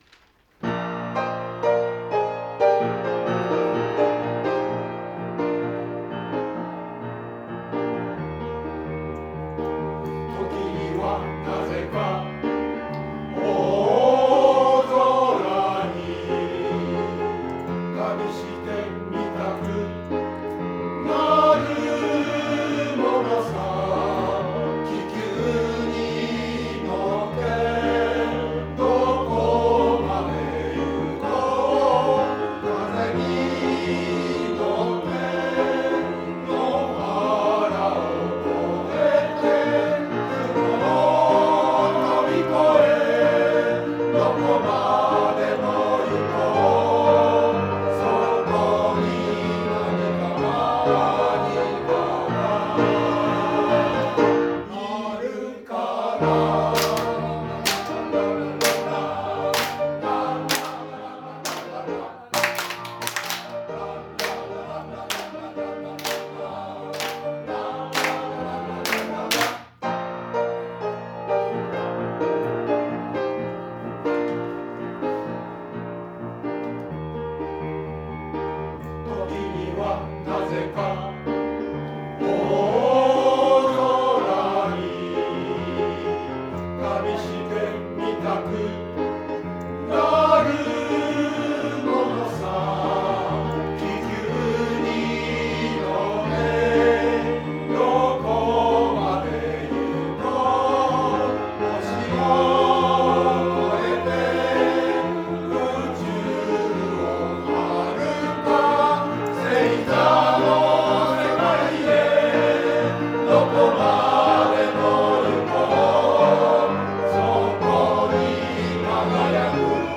合唱祭が近づく、湖北台市民センターでの練習